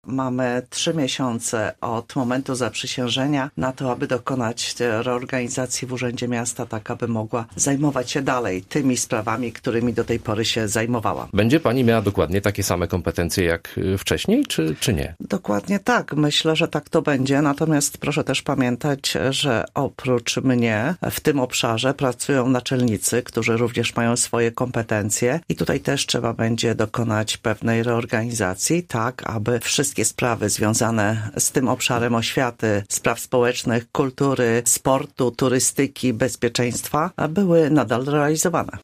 Wioleta Haręźlak, która była porannym gościem Radia Zachód, mówi, że zakres jej obowiązków pozostanie bez zmian: